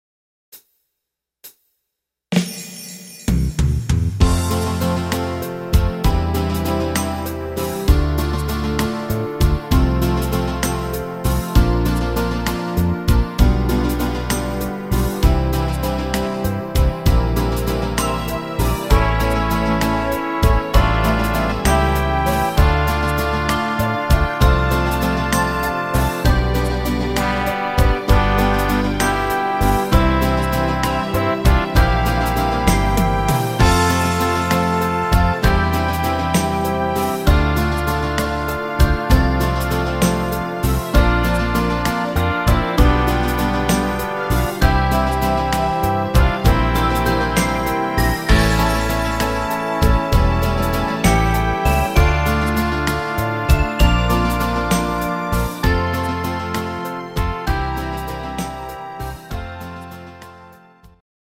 (instr.)